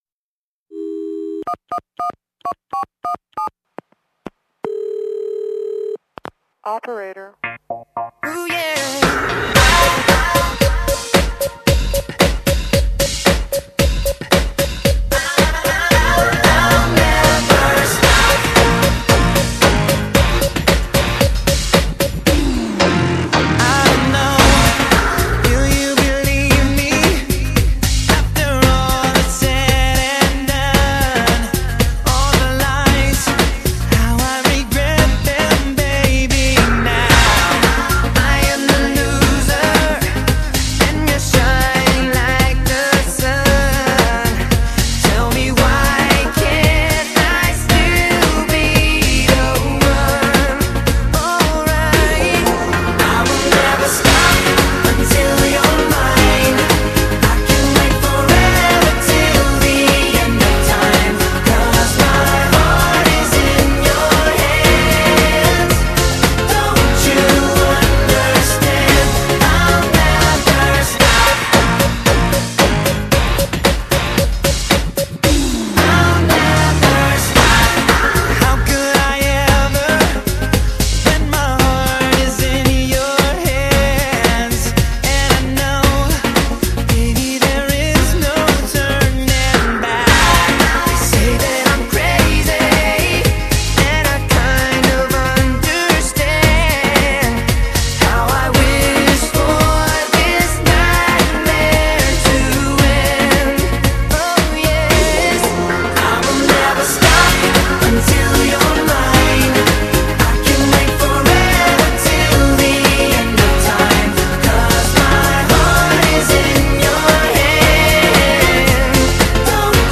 整张专辑涵盖了电子、节奏蓝调、舞曲、乡村音乐等多元素曲风。